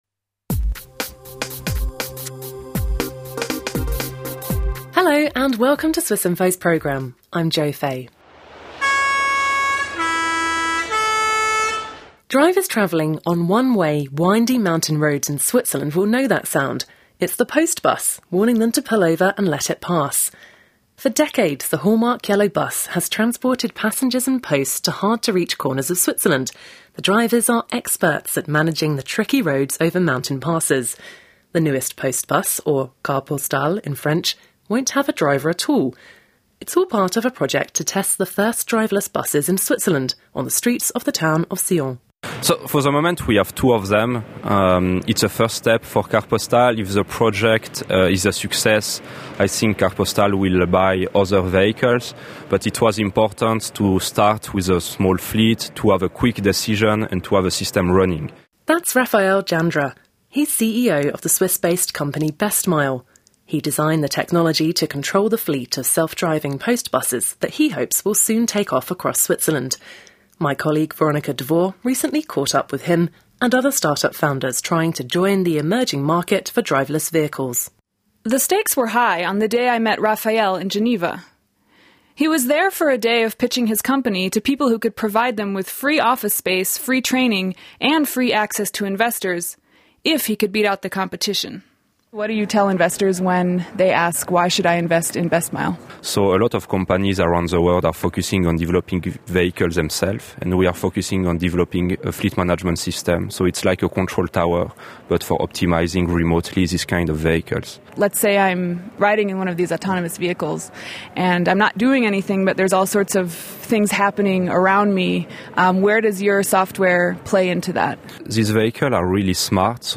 The founders of two Swiss startups making technologies for driverless cars explain their vision for the future of transport and predict how soon we'll be letting robots take the wheel.